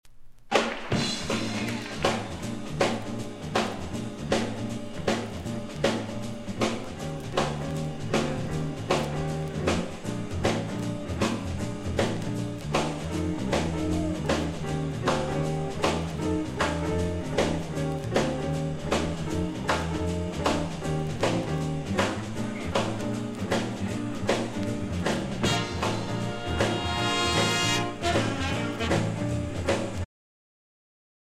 guitar
tenor saxophone
alto saxophone
Jazz